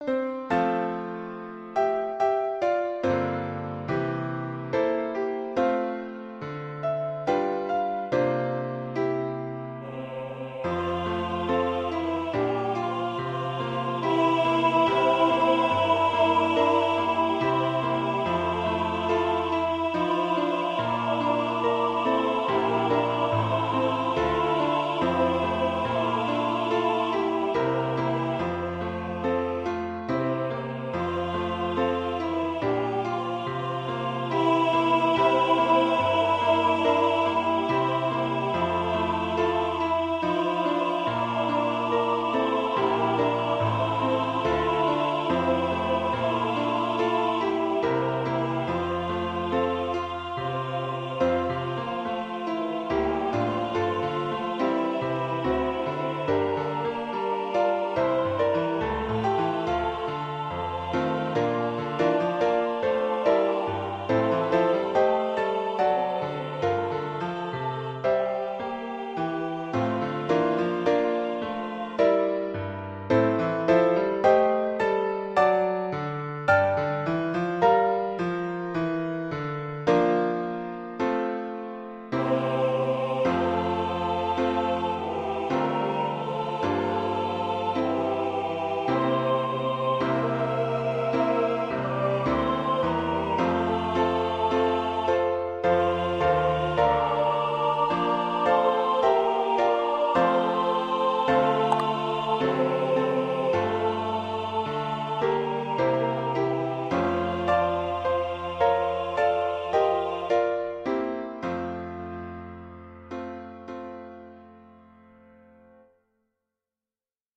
Ноты для фортепиано, вокальная партитура.
Русская народная песня